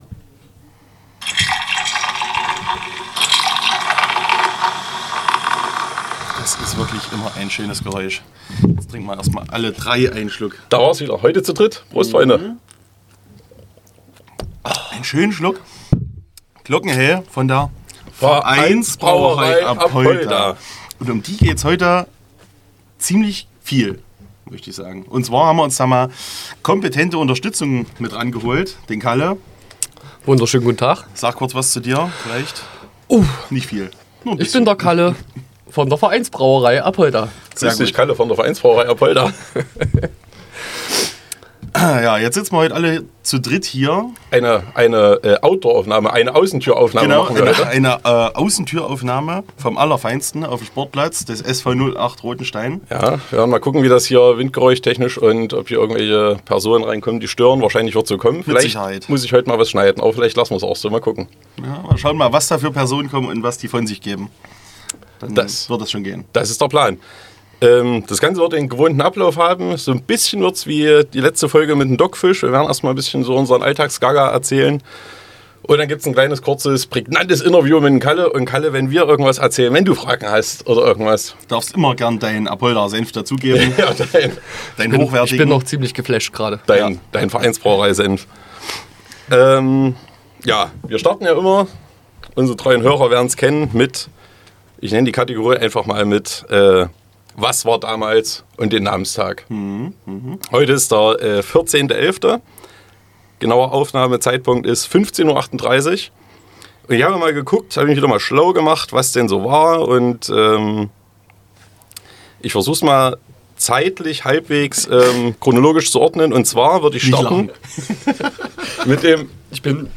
Beschreibung vor 4 Monaten Folge 15 mit maximal vielen Störgeräuschen und hohem Besuch. Live vom Sportplatz des SV 08 Rothenstein meldet sich Studio Finke. Während ringsherum alles für das jährliche Anglühen vorbereitet wird, nehmen wir Folge 15 auf.